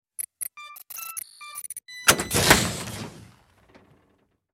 Звуки лаборатории
Здесь собраны реалистичные аудиоэффекты: от тихого гудения оборудования до звонких перекликов стеклянных колб.
Вот еще один вариант, но здесь добавлен ввод кода